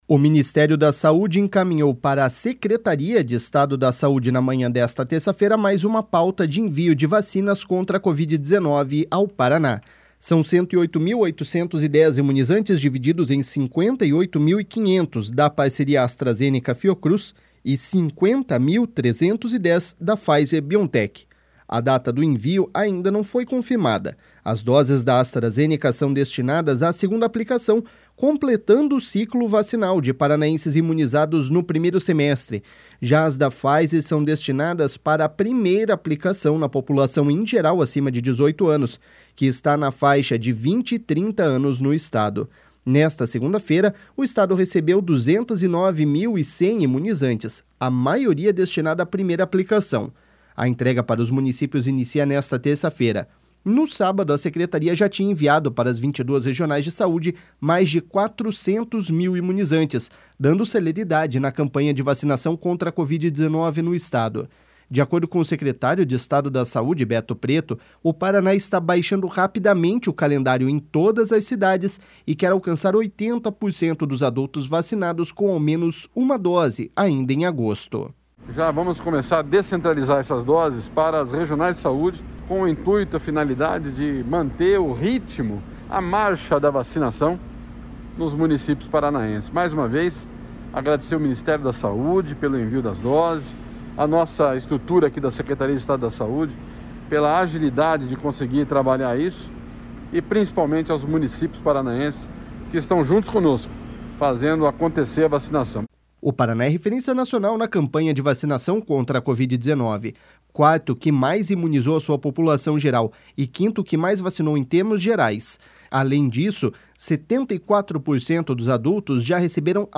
De acordo com o secretário de Estado da Saúde, Beto Preto, o Paraná está baixando rapidamente o calendário em todas as cidades e quer alcançar 80% dos adultos vacinados com ao menos uma dose ainda em agosto.// SONORA BETO PRETO.//